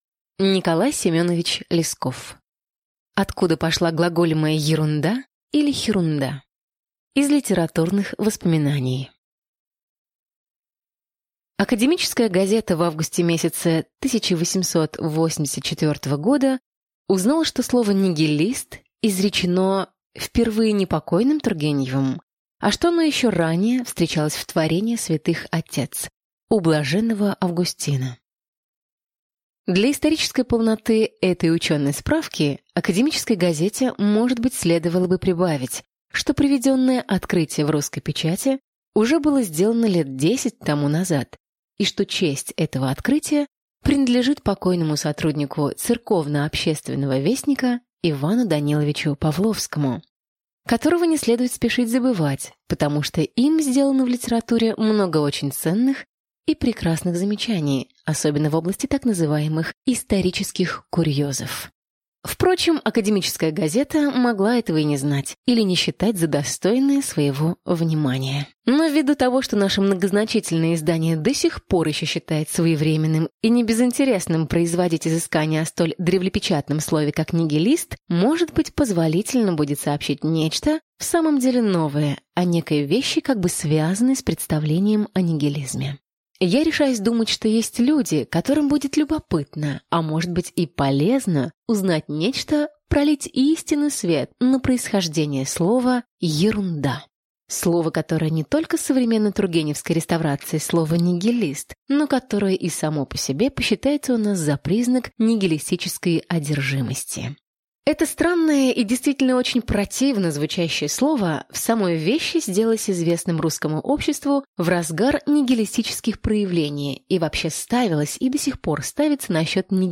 Аудиокнига Откуда пошла глаголемая «ерунда», или «хирунда» | Библиотека аудиокниг